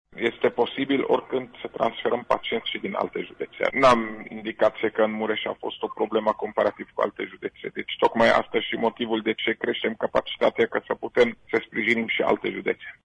Anunțul a fost făcut pentru Radio Tg Mureș de către șeful DSU, dr Raed Arafat.